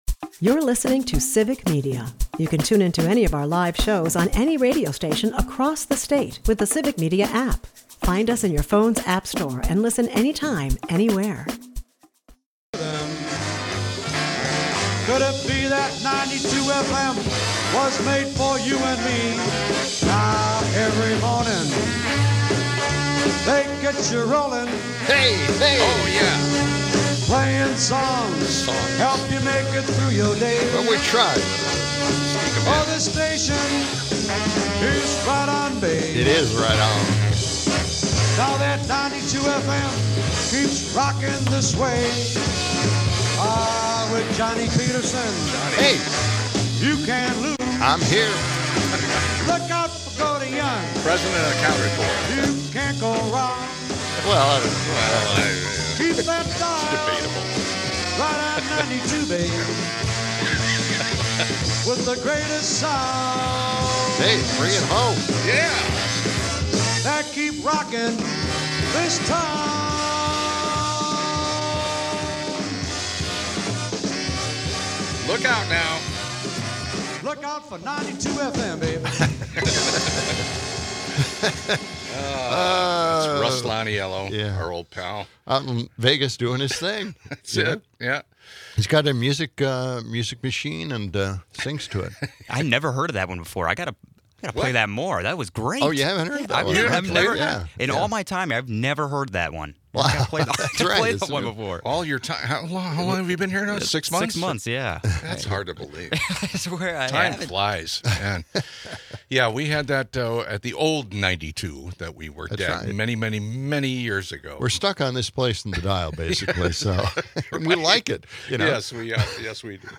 In political news, there's outrage over rumored Trump administration efforts to roll back fair lending rules for women. As the conversation lightens, they explore holiday films, with former Madison Mayor Paul Soglin sharing his favorites. Tech talk rounds out the show with promising news on sodium-ion batteries and adaptive shoes for mobility issues.